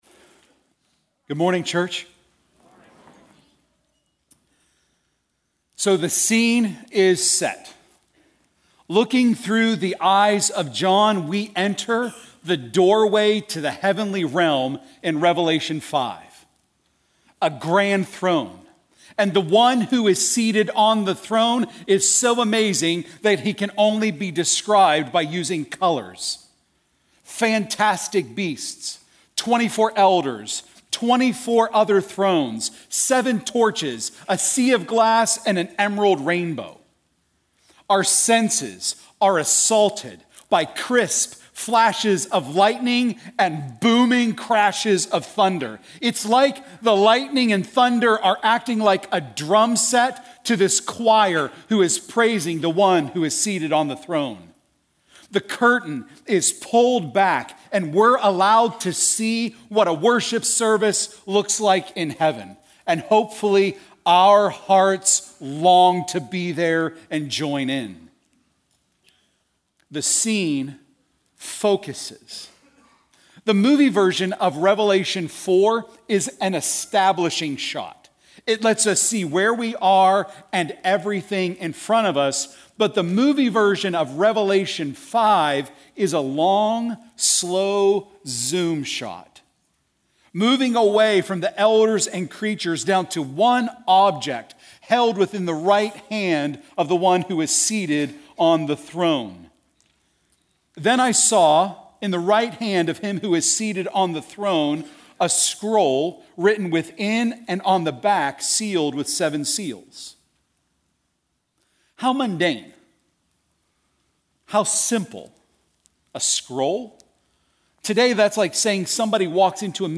Prev Previous Sermon Next Sermon Next Title Throne Room Series